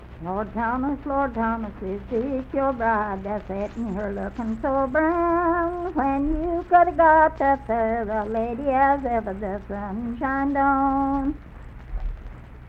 Unaccompanied vocal music
Ballads, Folk music--West Virginia, Coal miners--West Virginia
Voice (sung)